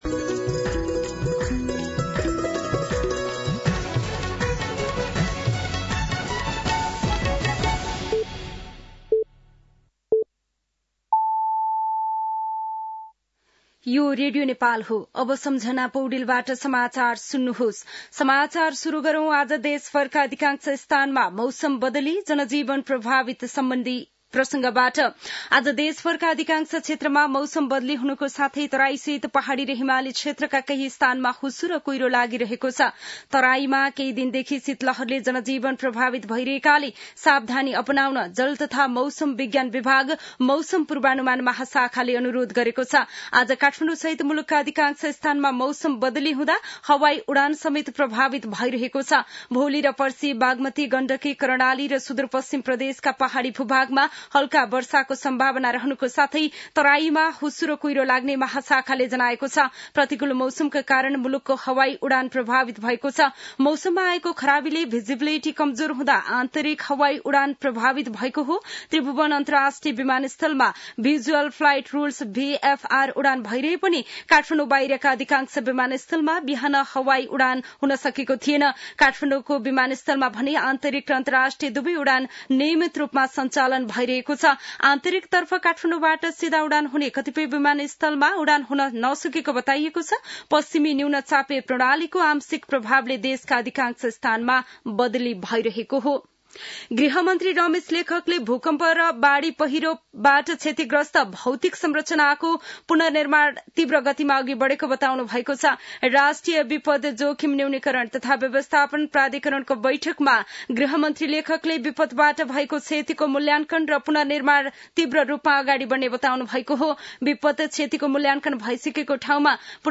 साँझ ५ बजेको नेपाली समाचार : २२ पुष , २०८१
5-pm-news.mp3